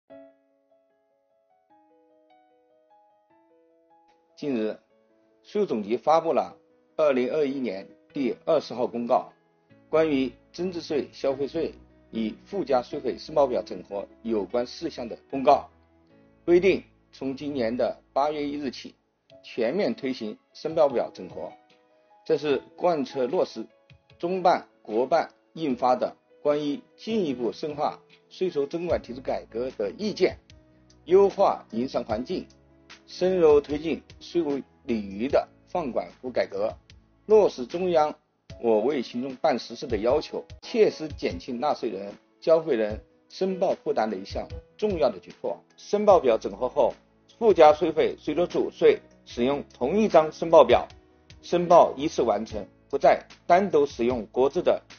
8月23日，国家税务总局推出最新一期“税务讲堂”网上公开课，税务总局货物和劳务税司副司长张卫详细解读增值税、消费税分别与附加税费申报表整合的背景意义、申报方法、主要变化和注意事项等，帮助纳税人缴费人更好了解政策、适用政策。